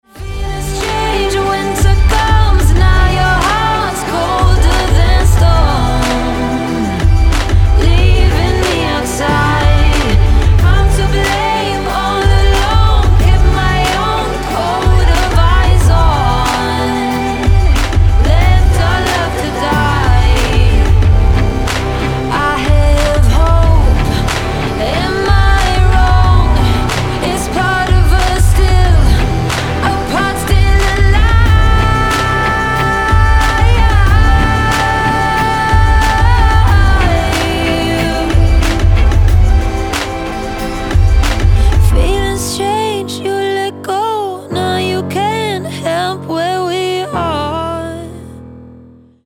• Качество: 320, Stereo
красивые
спокойные
нарастающие
красивый женский голос